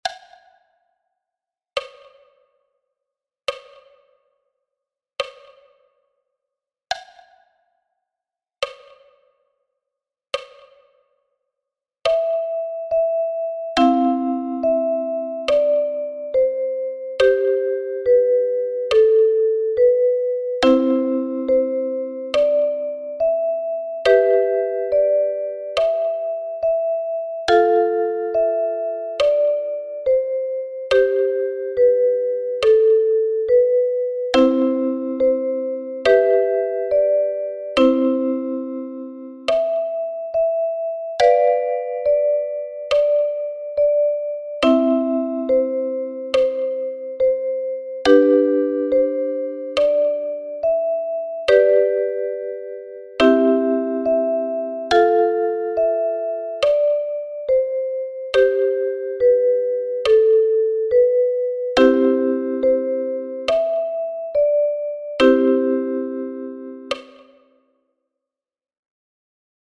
einfach arrangiert für eine/n Kalimba-Spieler/in.